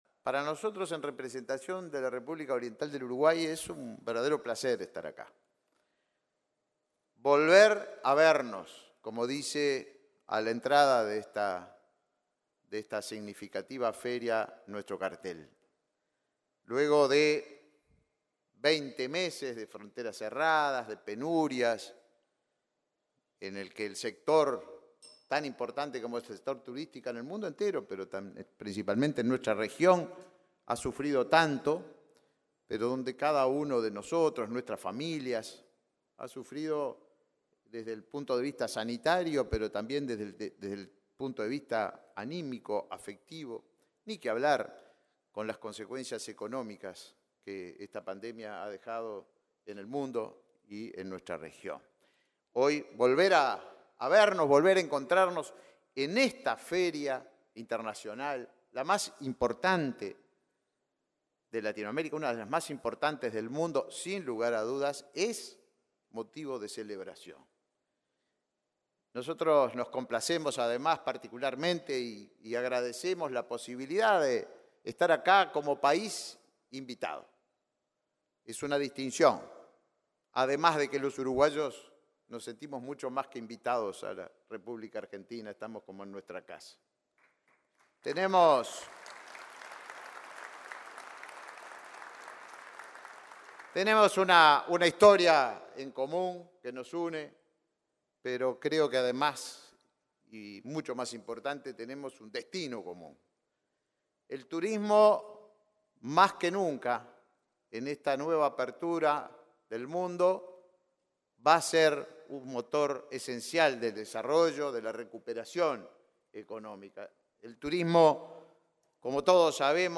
Disertación del ministro de Turismo, Tabaré Viera
El ministro de Turismo, Tabaré Viera, encabezó el acto de inauguración de la 25.ª edición de la Feria Internacional de Turismo de América Latina, en